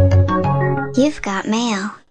Message Ringtones